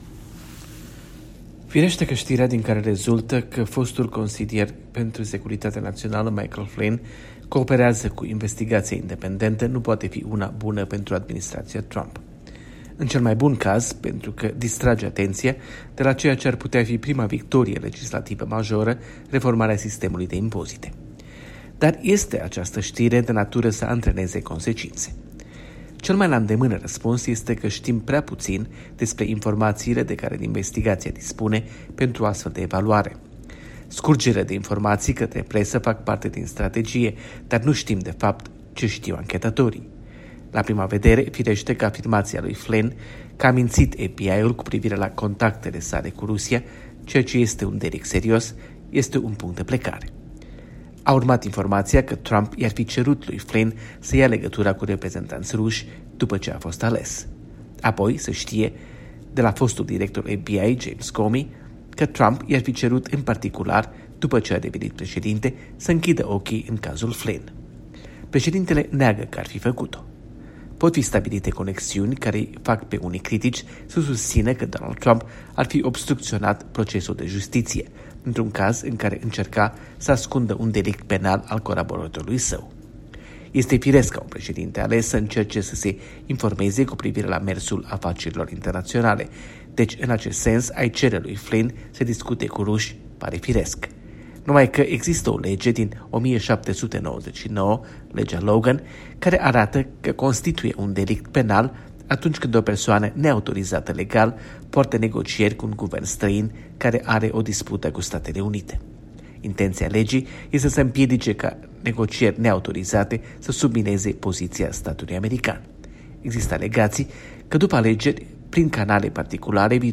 Corespondența zilei de la Washington.